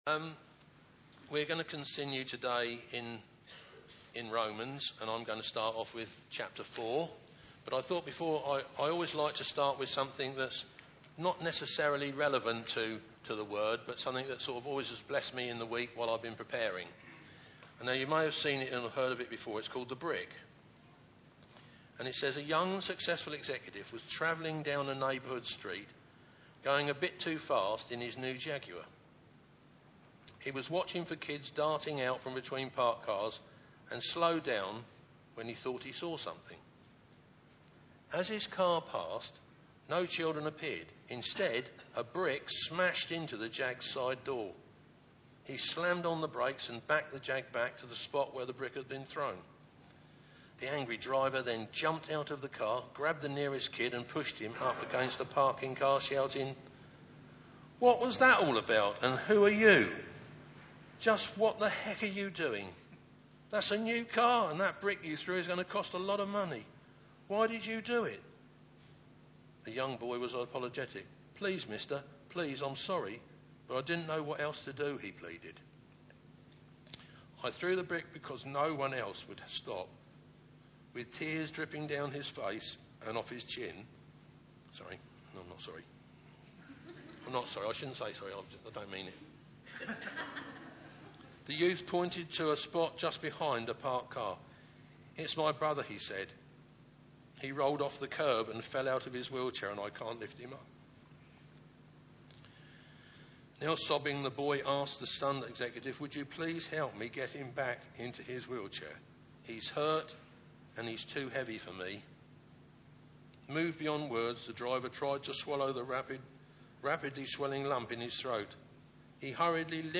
Service Type: Sunday Meeting